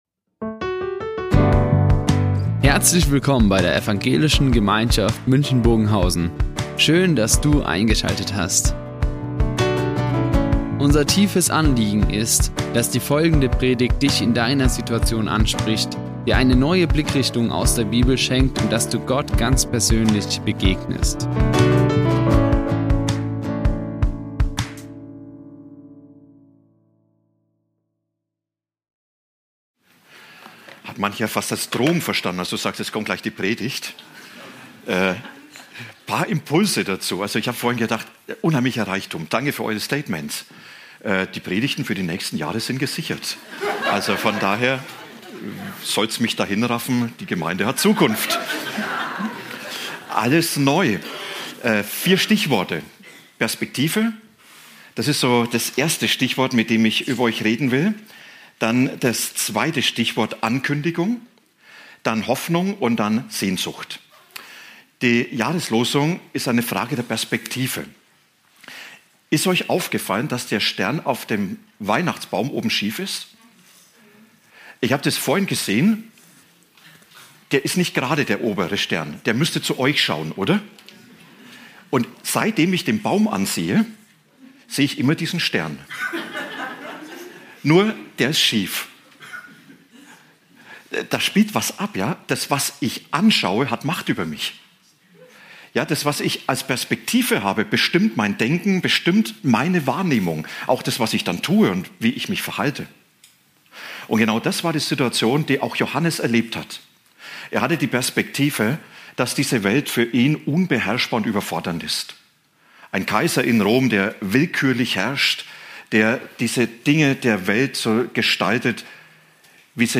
Alles neu | Predigt Offenbarung 21.5 ~ Ev. Gemeinschaft München Predigten Podcast
Die Aufzeichnung erfolgte im Rahmen eines Livestreams.